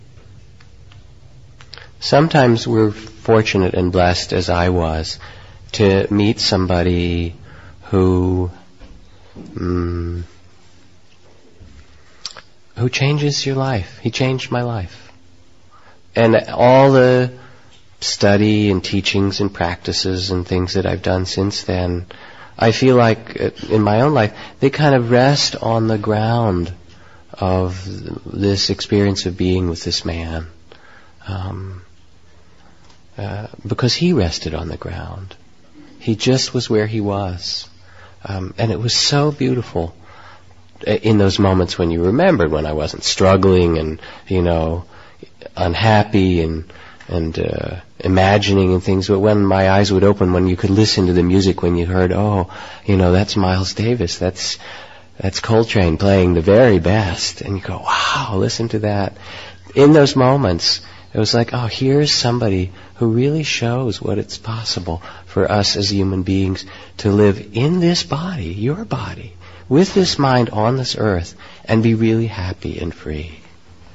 Reflection by Jack Kornfield.